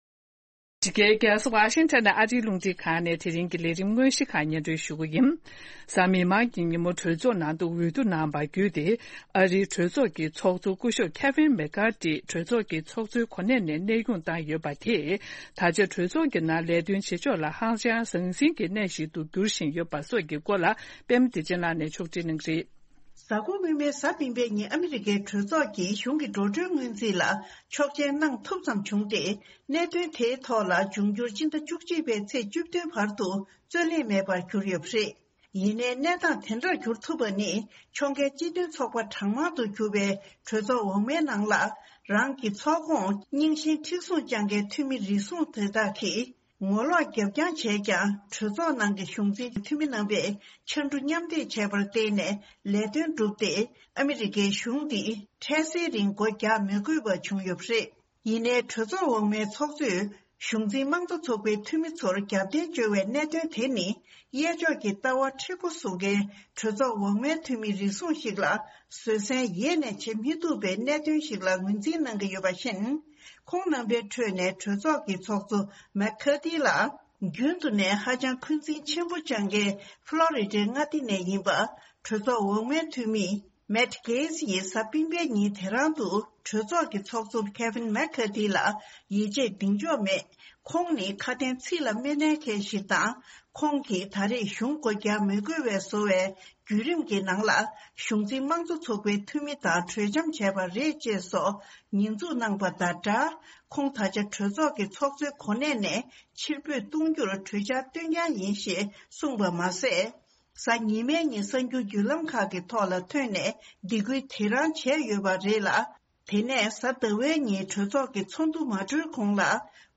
སྙན་སྒྲོན་རྗེས་གླེང་མོལ།